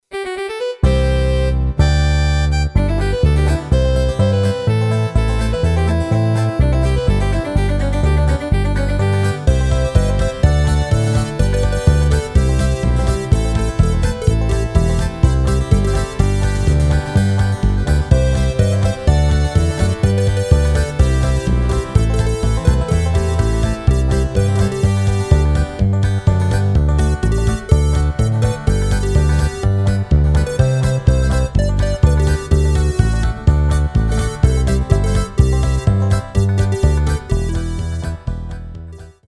Rubrika: Folk, Country